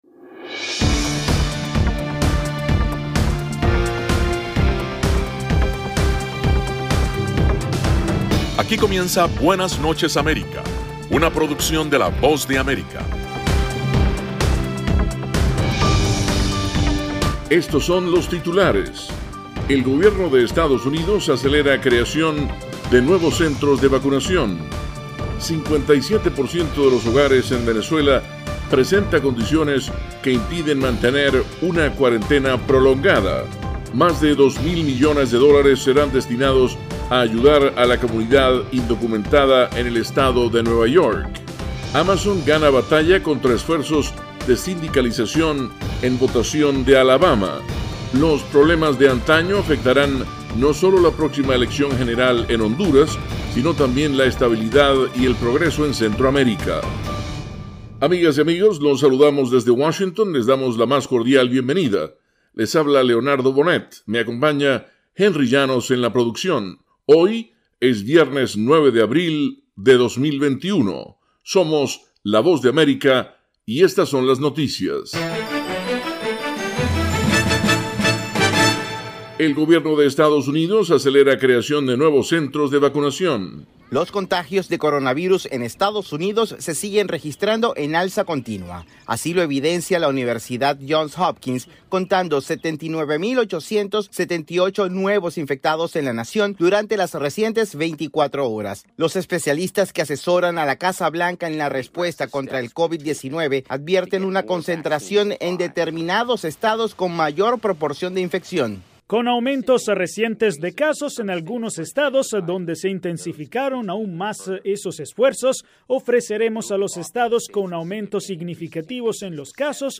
PROGRAMA INFORMATIVO DE LA VOZ DE AMERICA, BUENAS NOCHES AMERICA.